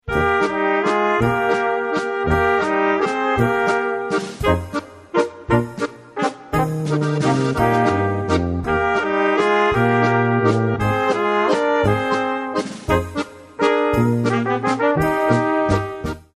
Volkstänze aus Niederösterreich